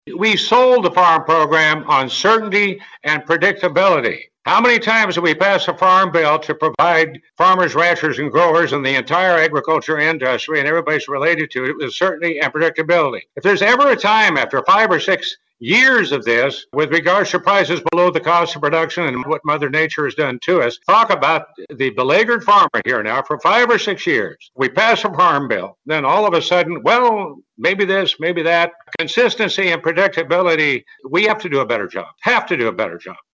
Chair of the Senate Ag Committee Pat Roberts spoke during a recent virtual event hosted by Agri-Pulse. He says ad-hoc disaster assistance isn’t the way to help US agriculture going forward, because it’s not sustainable. He says strengthening the safety net programs in the farm bill is the way to do it right.